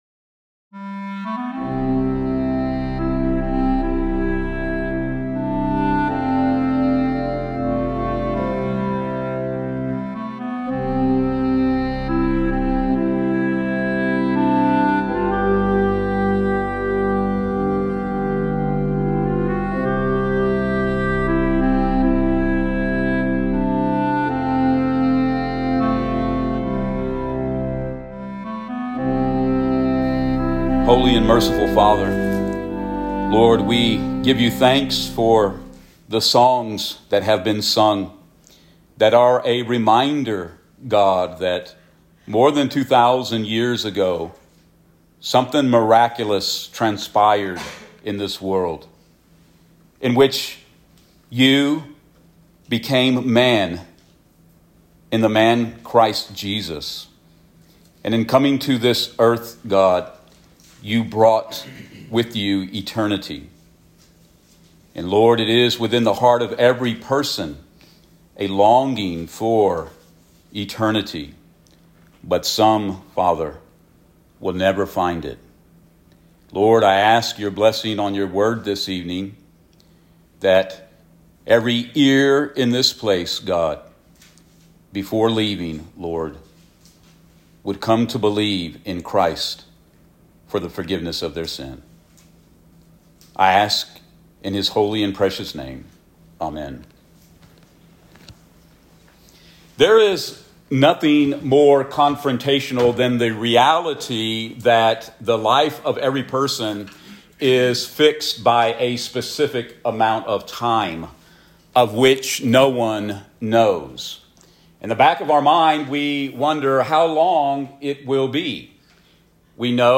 Christmas Sermons